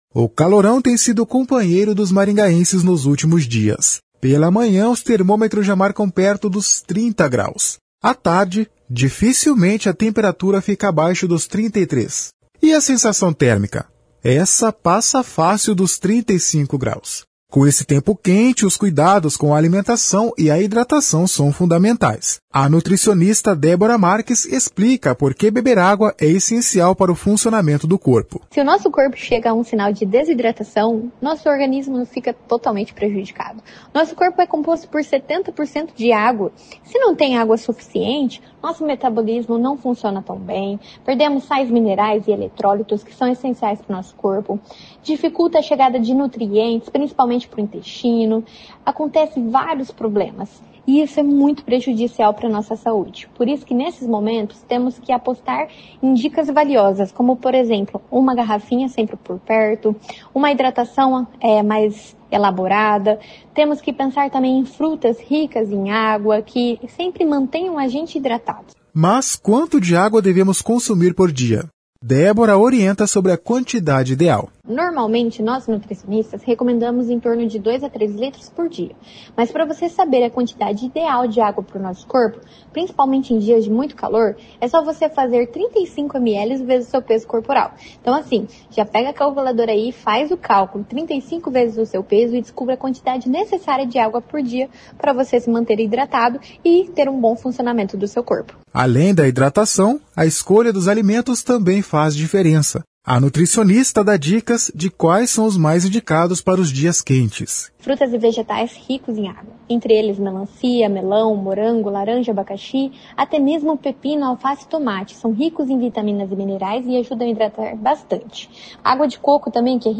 A nutricionista dá dicas de quais são os mais indicados para os dias quentes.